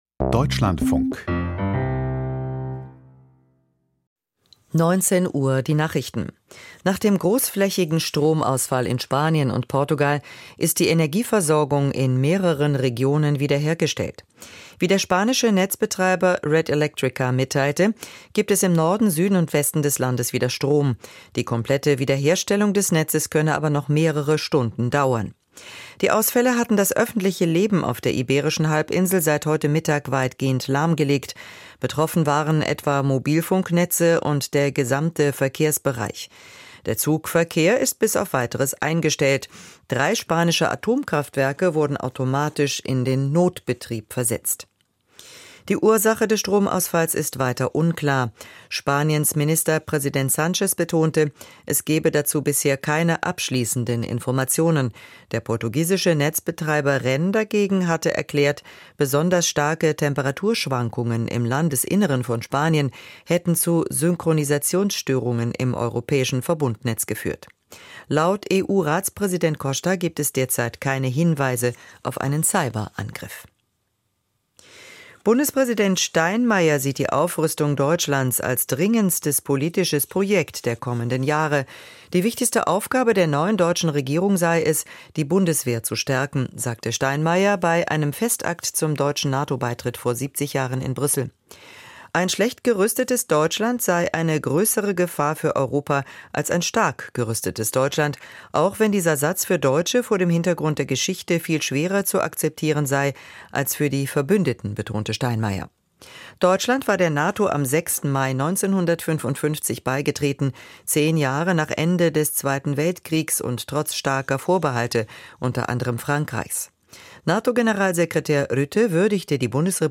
Die Deutschlandfunk-Nachrichten vom 28.04.2025, 19:00 Uhr